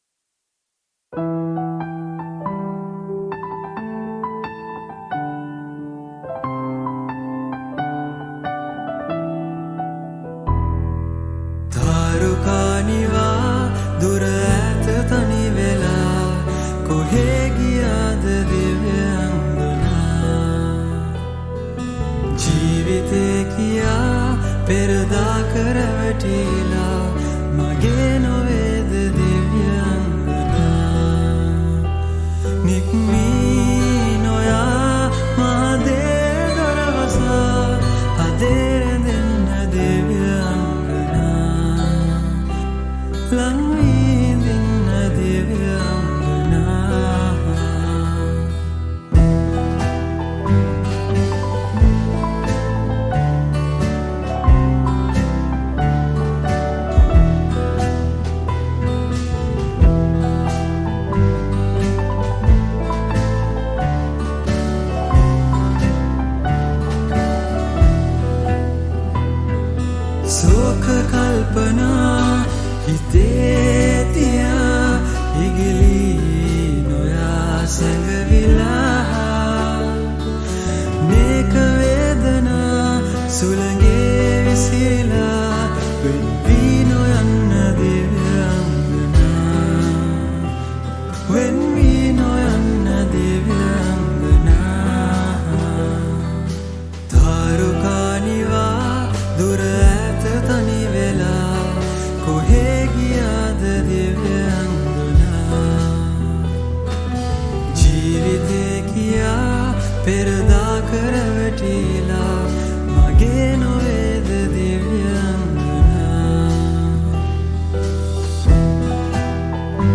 Cover
Cover vocals